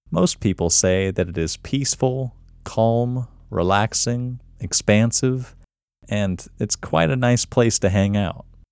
QUIETNESS Male English 17
The-Quietness-Technique-Male-English-17.mp3